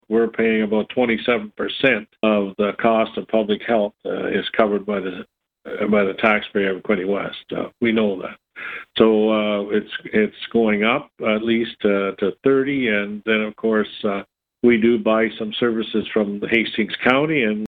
Quinte West Mayor Jim Harrison told Quinte News the announcement is another case of downloading.